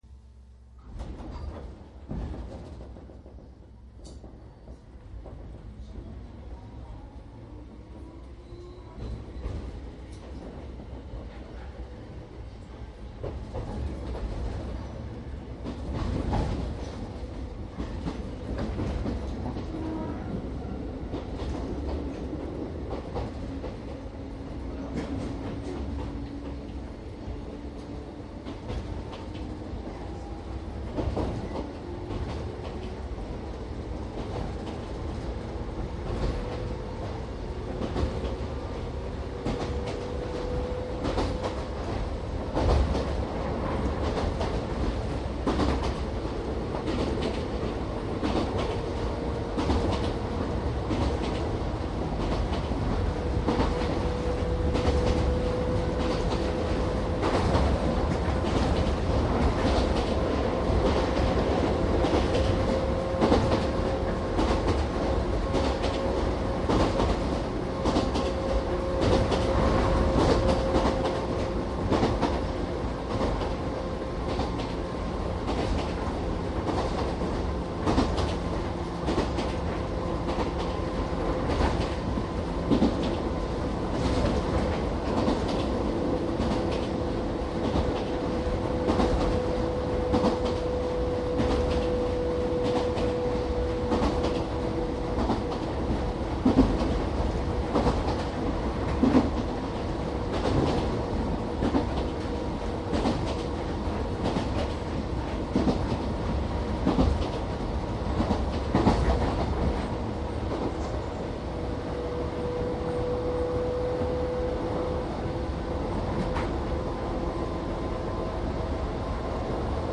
★☆鉄道走行車内音CD 秩父鉄道１０００形☆★
羽生線と秩父線で7年程録音差があります。この間に自動放送が更新されたので放送の声が若干異なります。沿線は遮断機がない踏切が多いので走行中によく急停車します。
Disk1  秩父鉄道 各停ワン々ン  羽生→熊谷
収録車両： デハ１００９
収録機材： ソニー（DATかMD） デジタル音源
収録マイク： ソニーECM959